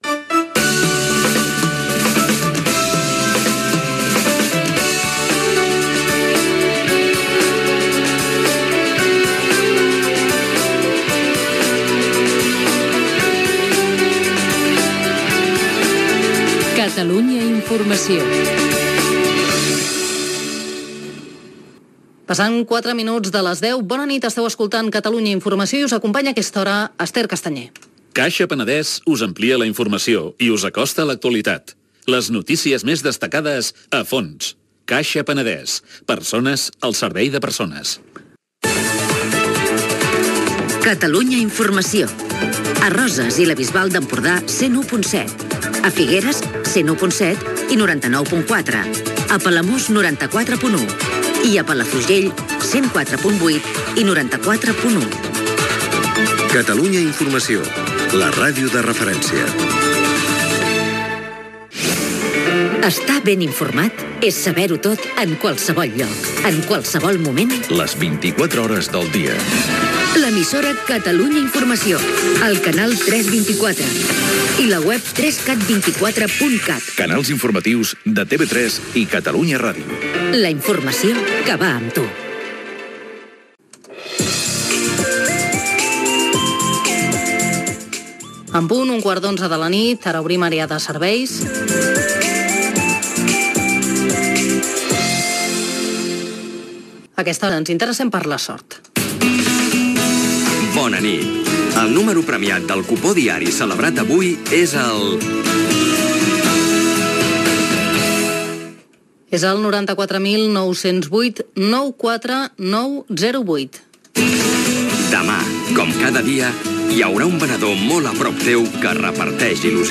Indicatiu de l'emissora, hora, publicitat, freqüències, webs informatives, número guanyador del sorteig de l'ONCE, pas a la crònica esportiva
Informatiu
FM